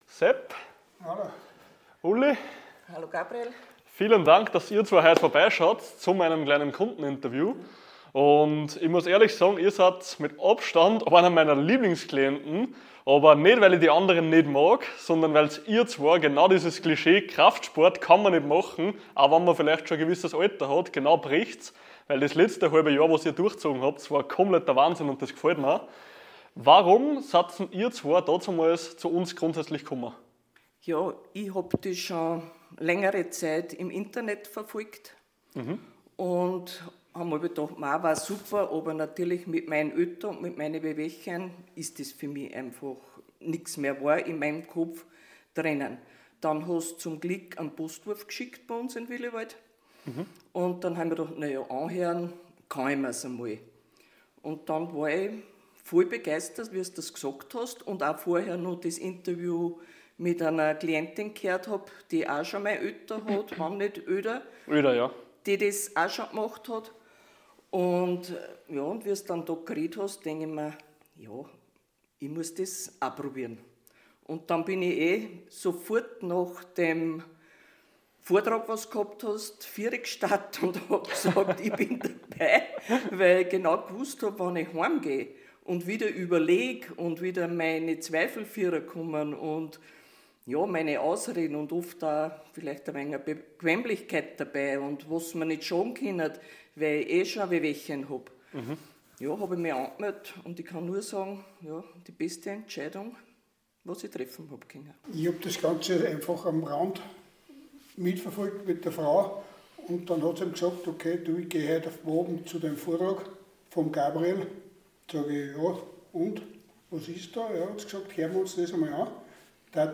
Kundeninterview